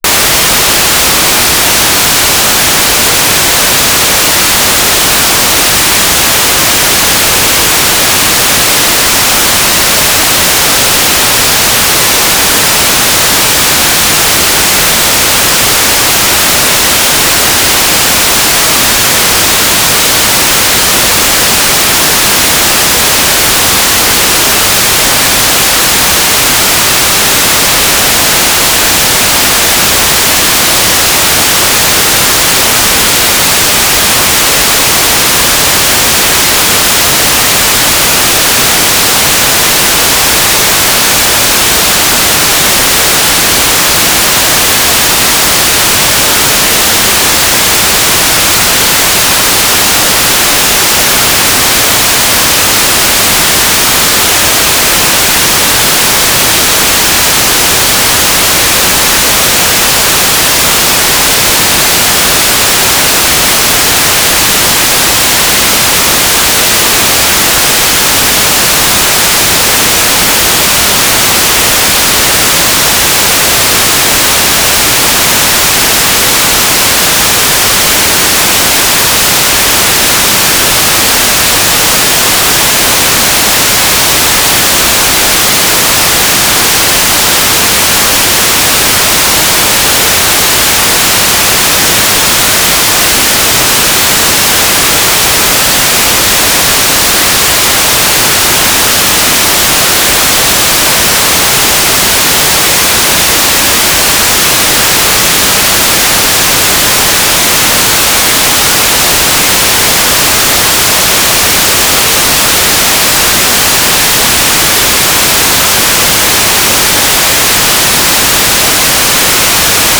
"transmitter_description": "Mode U - GMSK2k4 - USP",
"transmitter_mode": "GMSK USP",